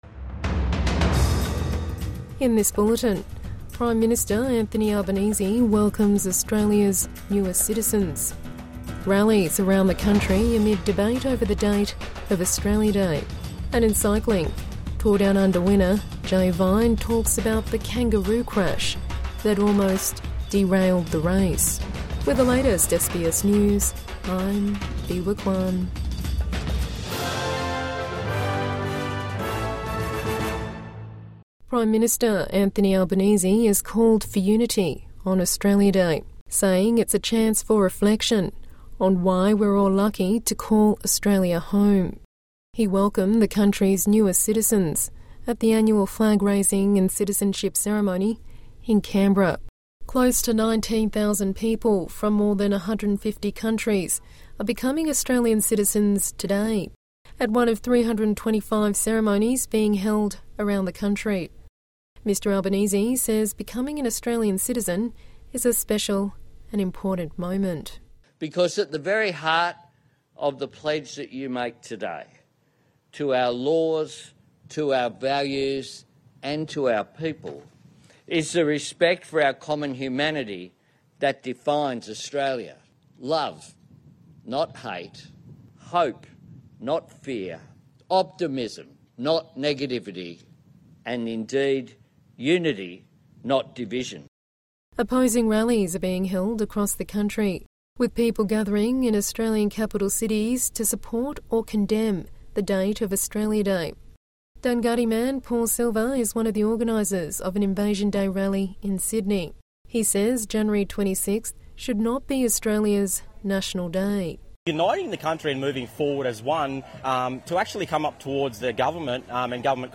PM welcomes Australia's newest citizens | Midday News Bulletin 26 January 2026